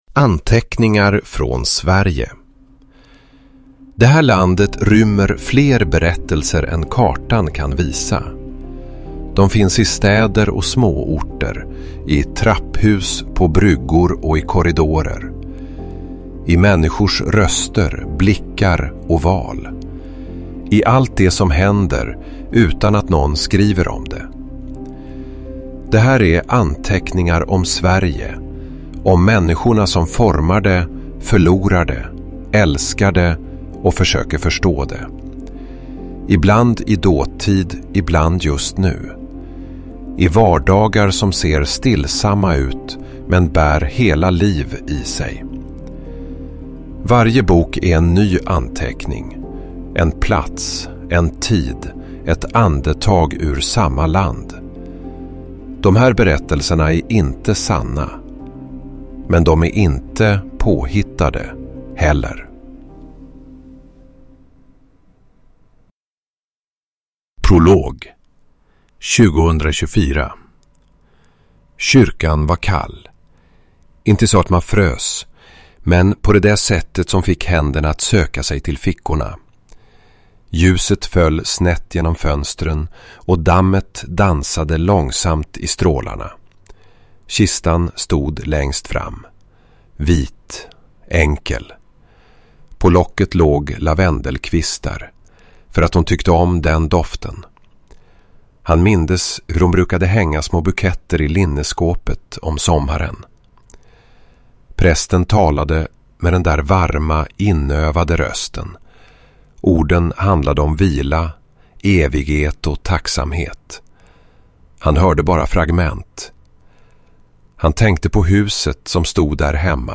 Ingemar – Ljudbok